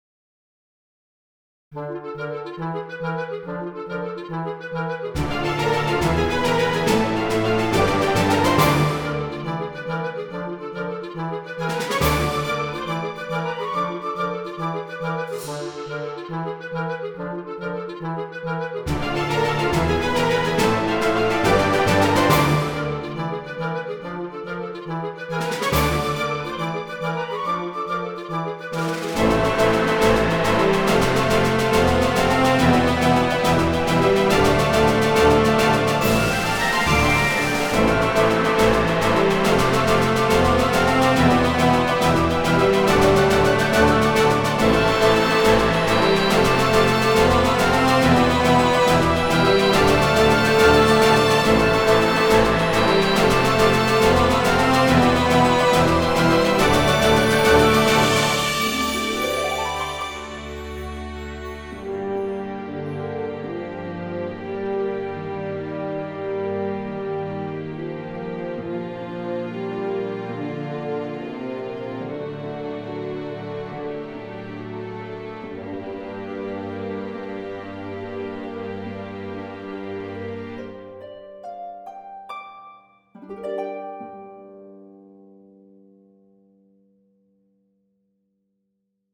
Very orchestral and good sounding.
Quelle finesse, quel sens de l'orchestration !